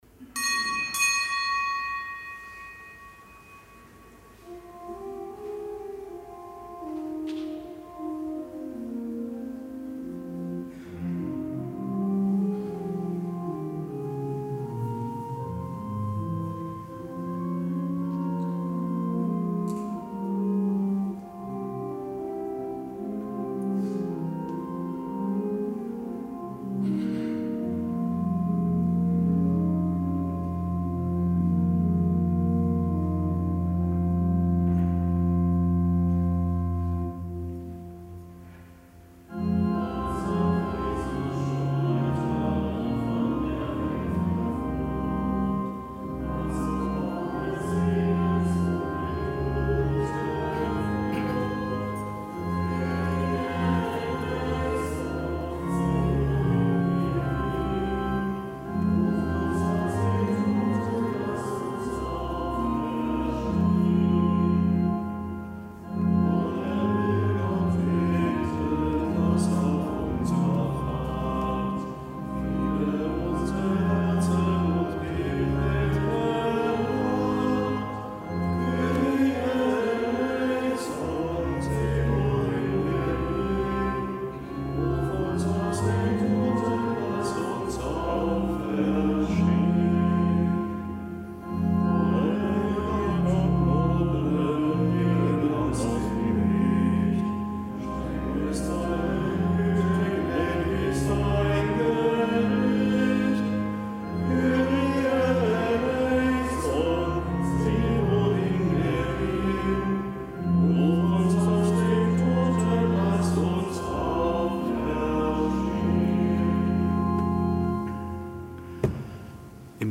Kapitelsmesse am Samstag der zweiten Fastenwoche
Kapitelsmesse aus dem Kölner Dom am Samstag der zweiten Fastenwoche; Gedenktag der Heiligen Perpetua und der Heiligen Felicitas, Märtyrinnen in Karthago.